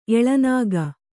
♪ eḷanāga